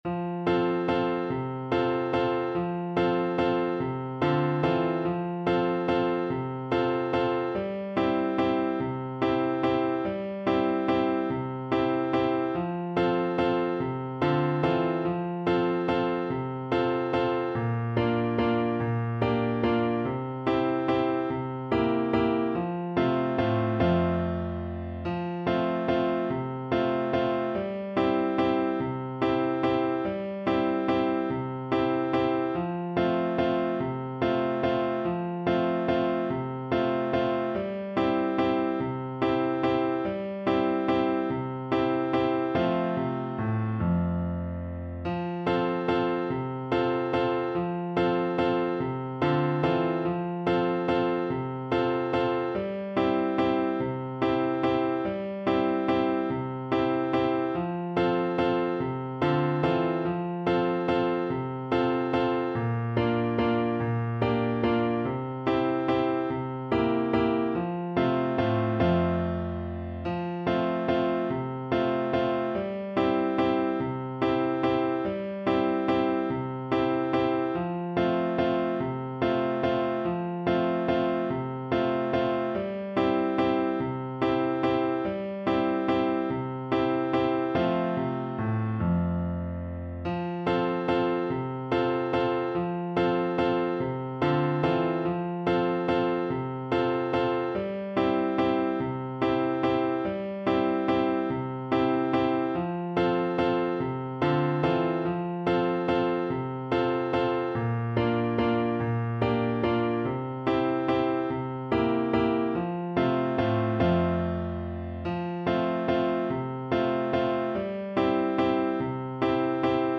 Steady one in a bar .=c.48
3/8 (View more 3/8 Music)